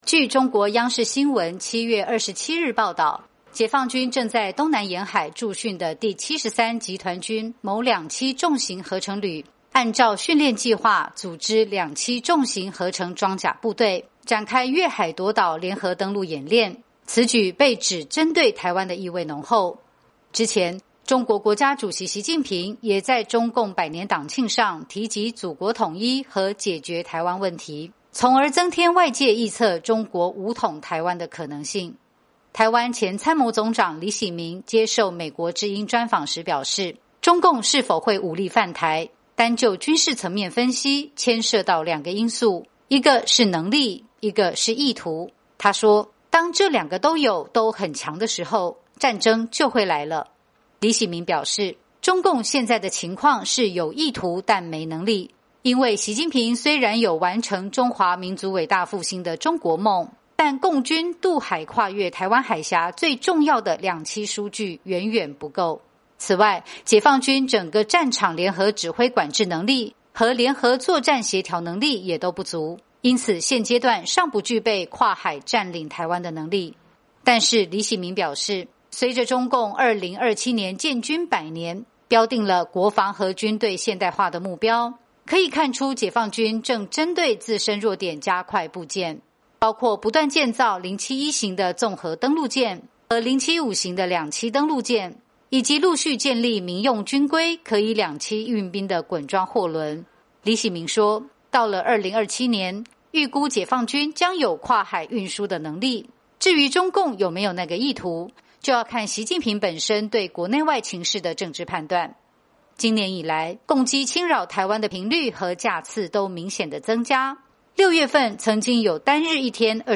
专访台湾前参谋总长：建构“刺猬台湾”靠自身实力拒止共军